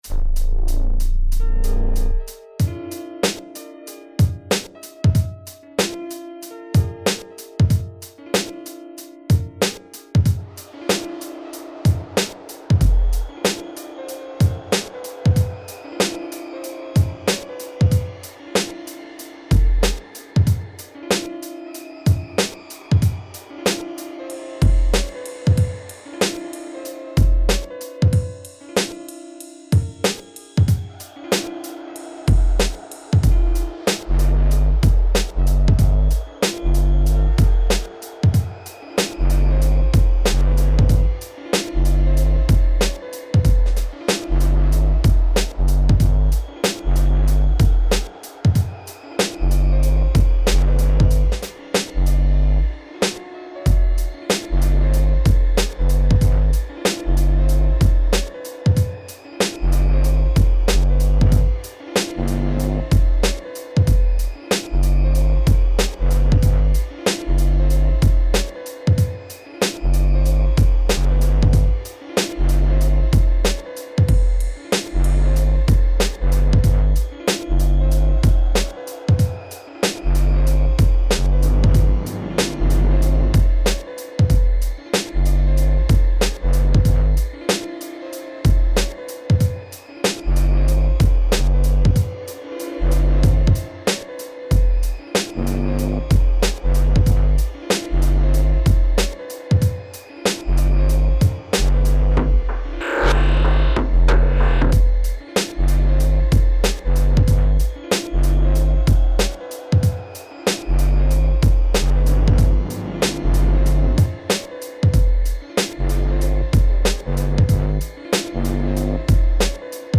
Techno, Dark Ambient, Dub Indus, Breakbeat...